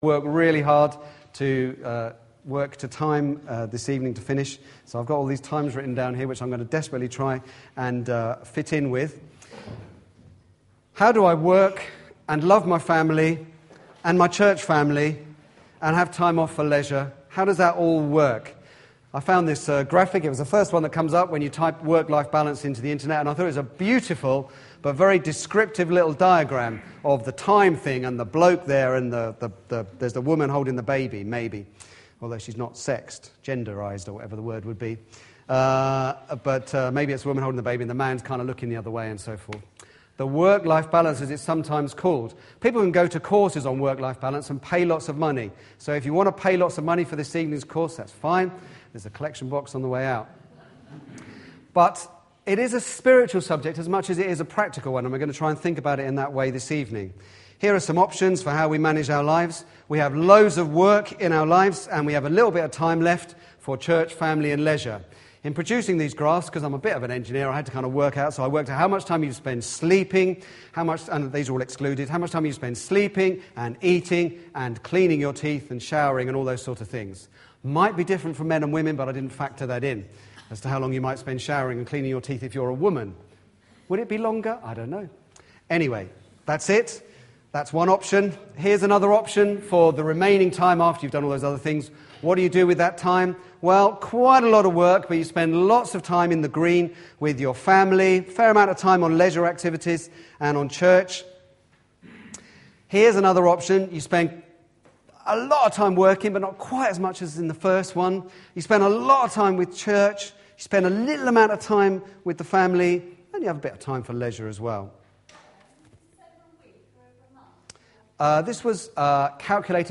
An independent evangelical church